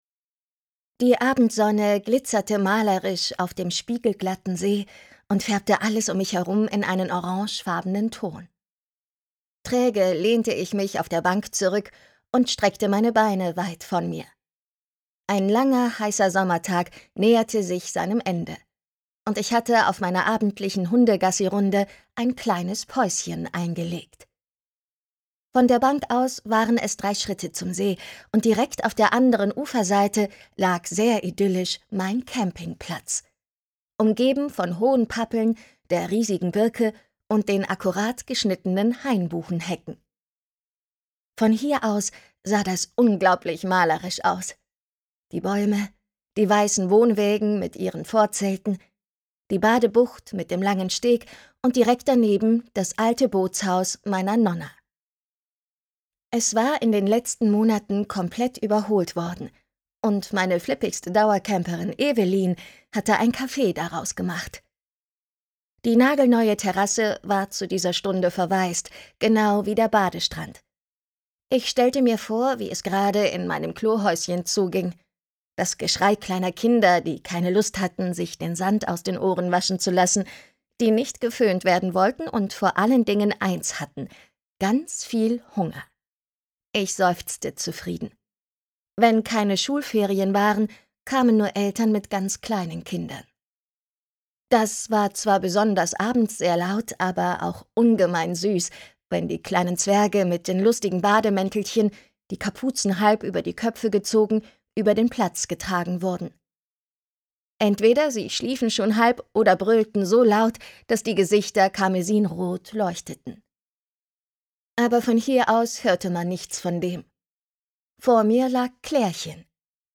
Der Tod liegt unterm Sonnenschirm Sofia und die Hirschgrund-Morde - Bayernkrimi Teil 9. Ungekürzt .